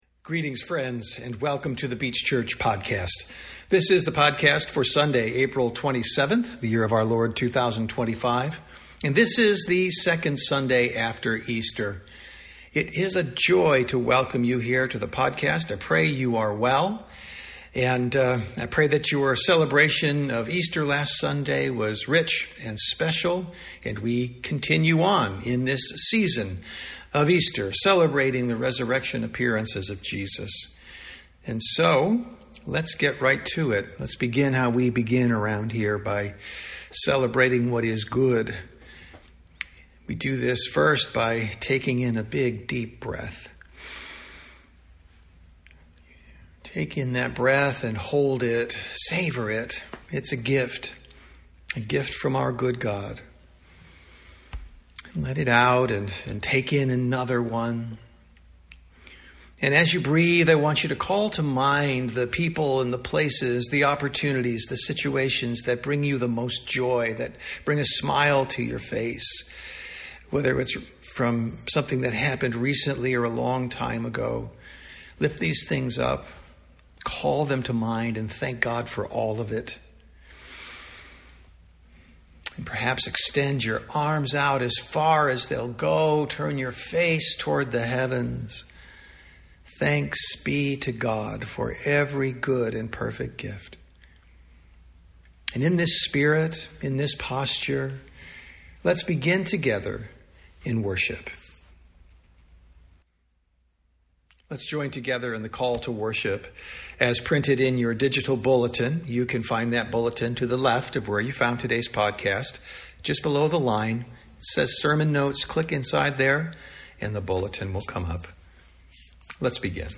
Sermons | The Beach Church
Sunday Worship - April 27, 2025